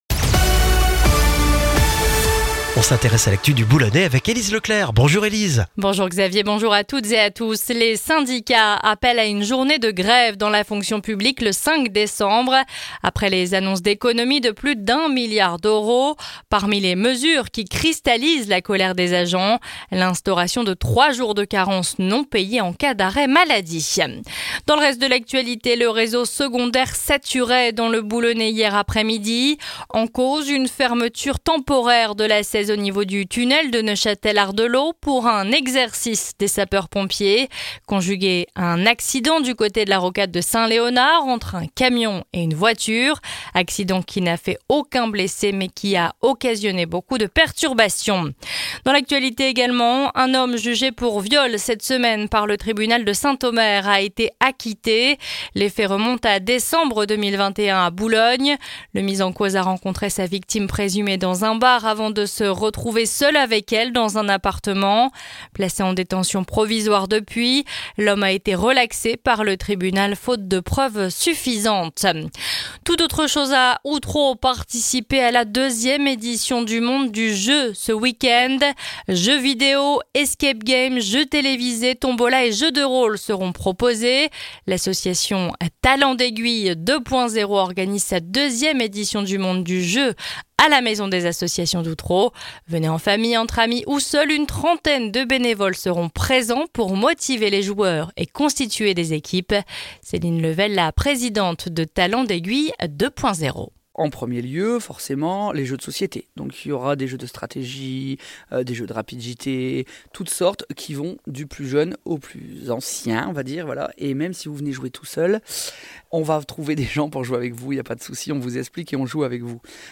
Le journal du vendredi 15 novembre dans le Boulonnais